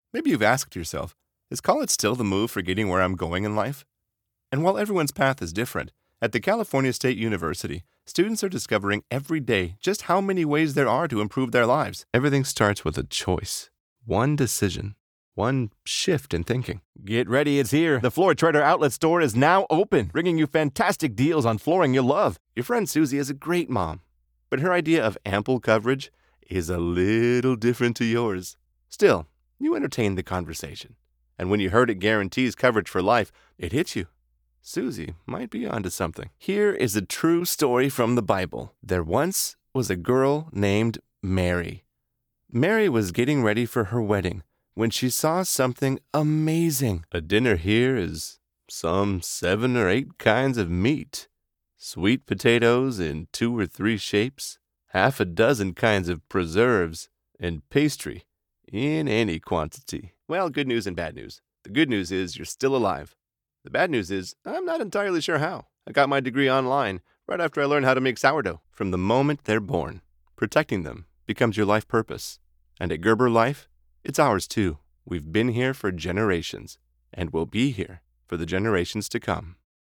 Professional Voice Actor and Educator
Demos
Middle Aged
Senior
Commercial
Medical Narration